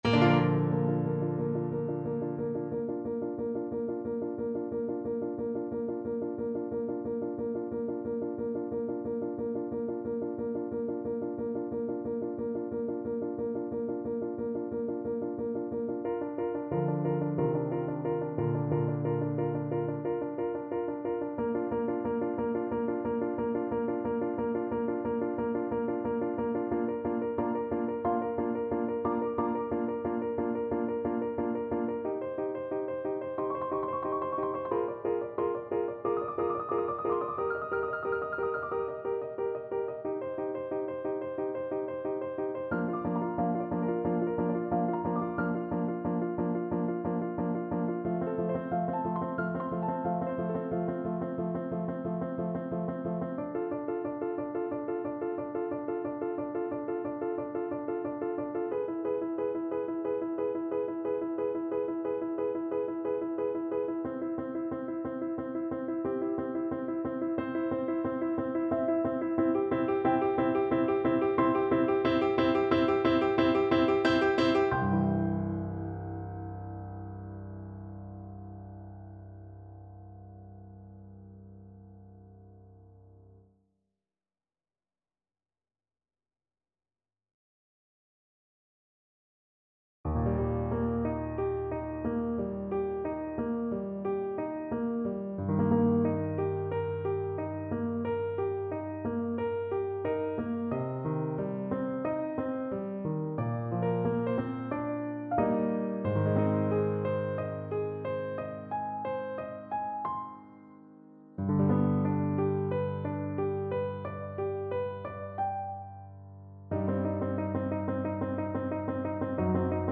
4/4 (View more 4/4 Music)
Andante =60
Classical (View more Classical Tenor Voice Music)